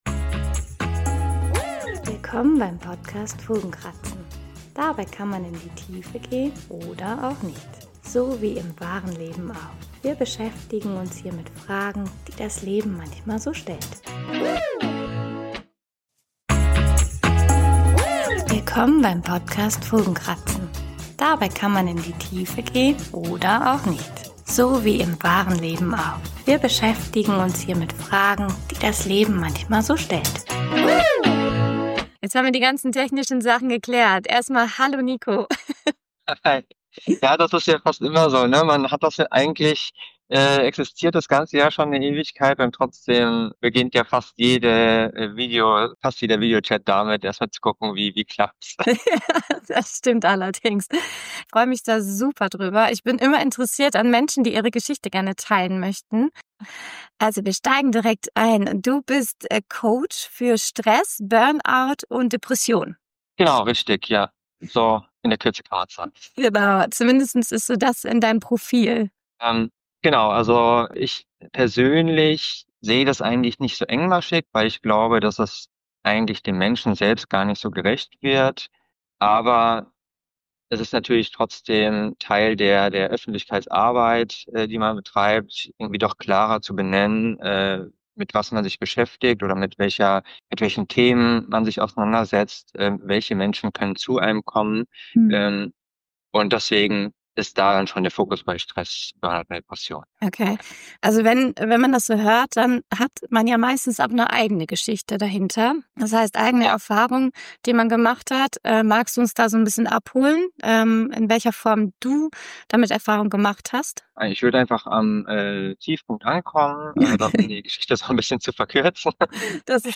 Januar 2026 Nächste Episode download Beschreibung Kapitel Teilen Abonnieren In dieser Folge ist alles ein bisschen anders als sonst: Zum ersten Mal habe ich einen Gast im Podcast.